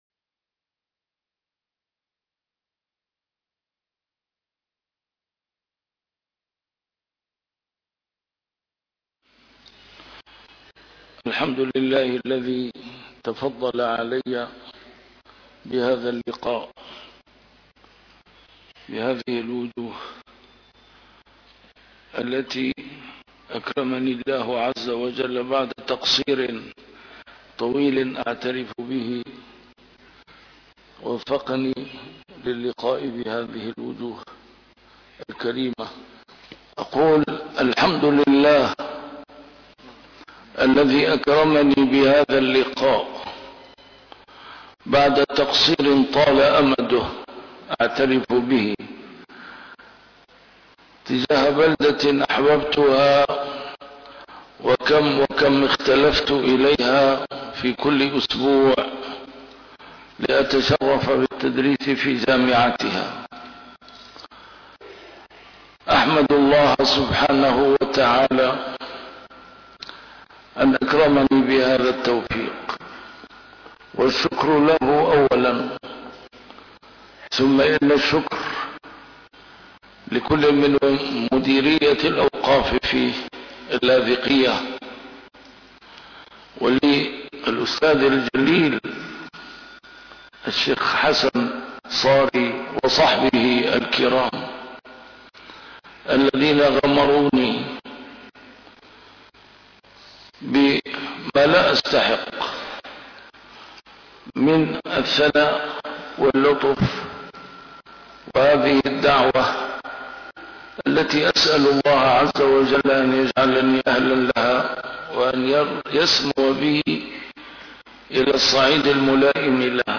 محاضرات متفرقة في مناسبات مختلفة - A MARTYR SCHOLAR: IMAM MUHAMMAD SAEED RAMADAN AL-BOUTI - الدروس العلمية - محاضرة بعنوان: شخصية رسول الله صلى الله عليه وسلم إماماً للدولة الإسلامية